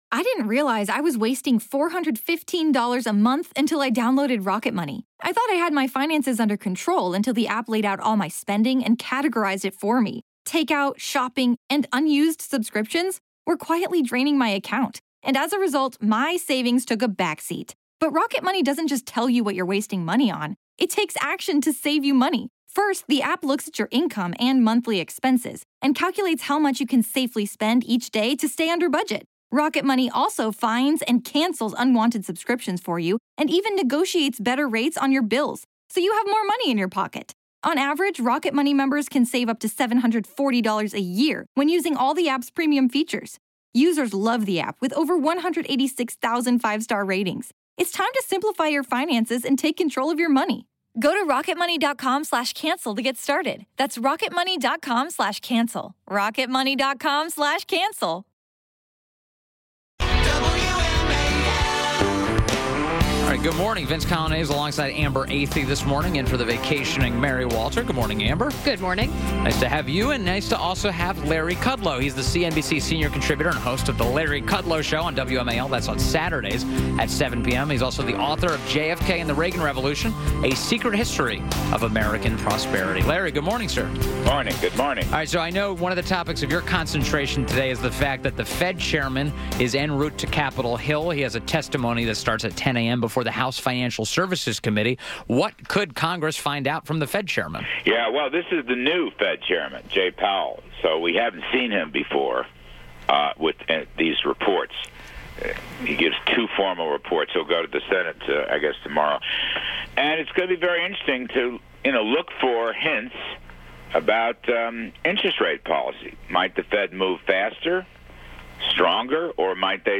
WMAL Interview - LARRY KUDLOW - 02.27.18